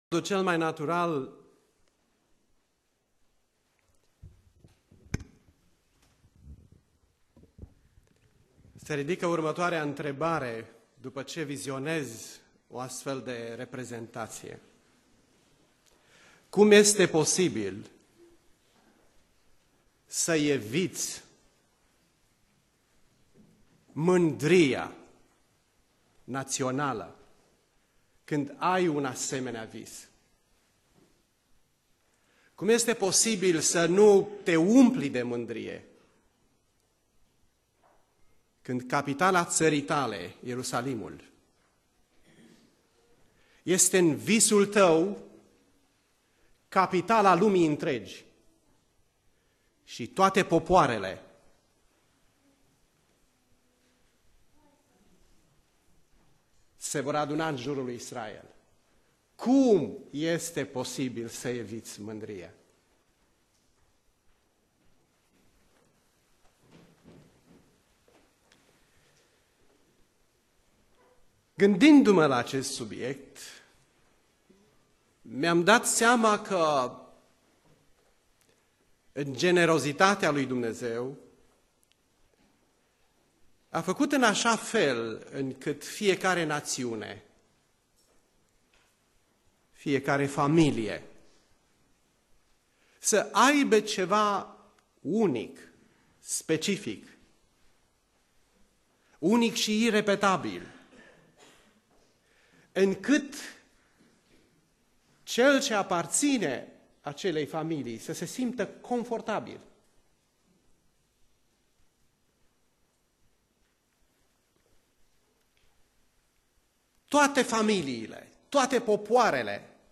Predica Aplicatie - Ieremia cap. 13